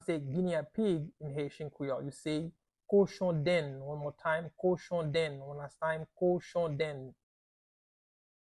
Pronunciation:
20.How-to-say-Guinea-Pig-in-Haitian-Creole-–-Kochondenn-pronunciation-by-a-Haitian-teacher.mp3